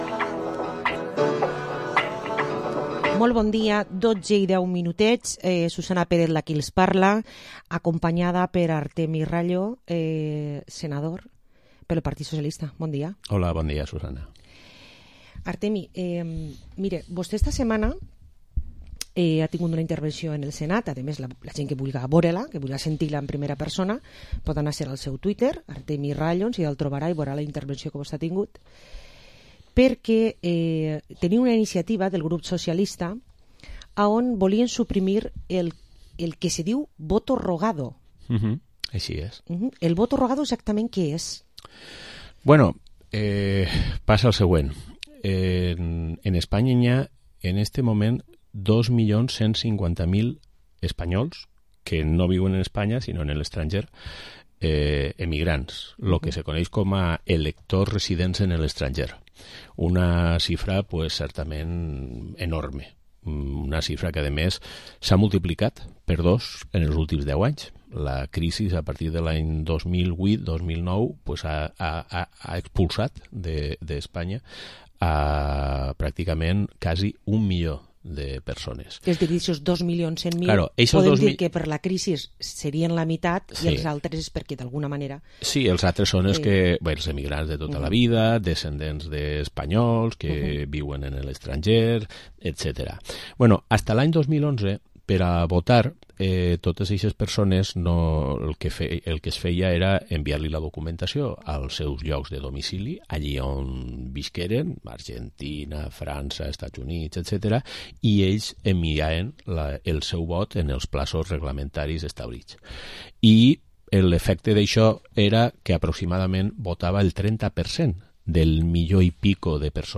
Entrevista al senador del PSPV por Castellón, Artemi Rallo